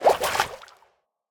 1.21.5 / assets / minecraft / sounds / entity / fish / swim6.ogg
swim6.ogg